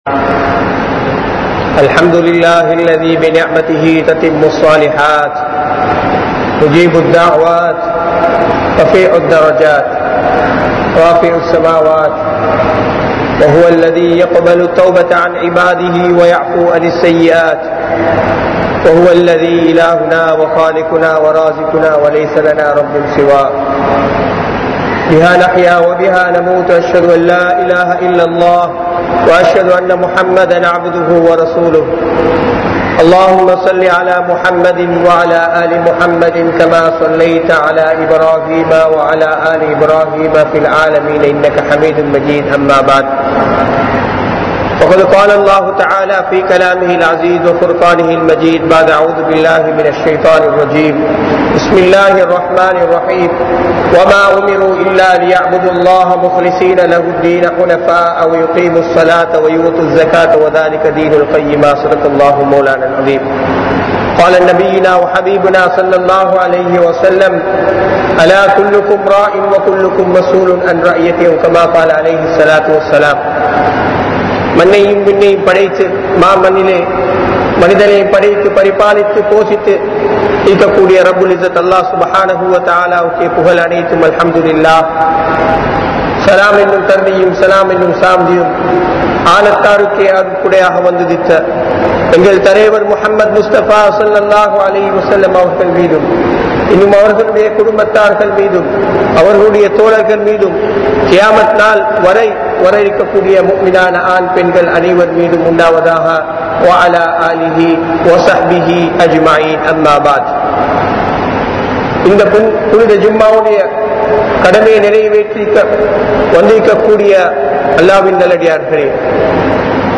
Ramalaanukku Thayaaraahungal (ரமழானுக்கு தயாராகுங்கள்) | Audio Bayans | All Ceylon Muslim Youth Community | Addalaichenai
Colombo 02, Wekanda Jumuah Masjidh